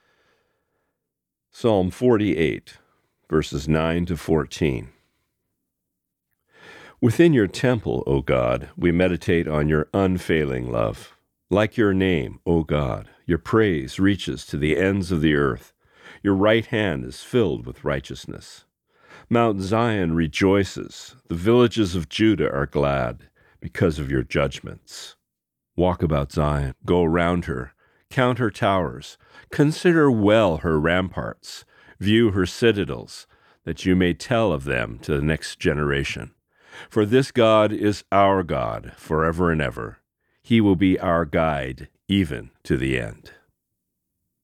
Reading: Psalm 48:9-14